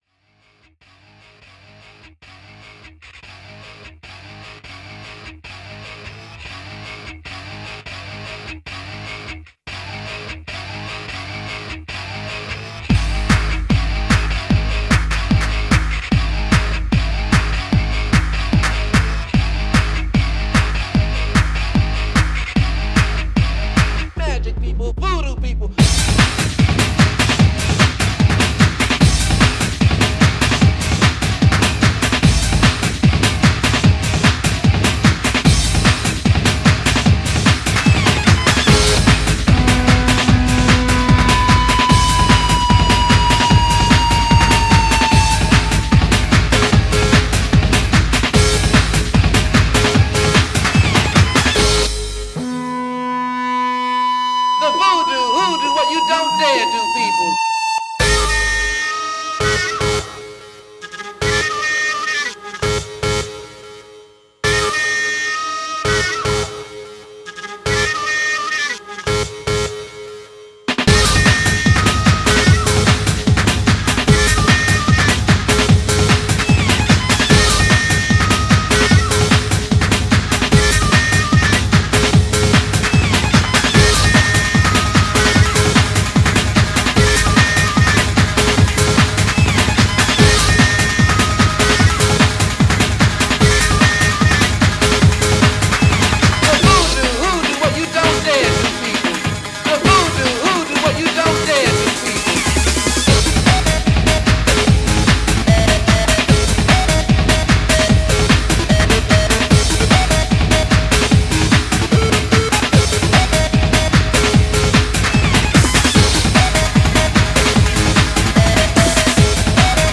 BPM149
Audio QualityLine Out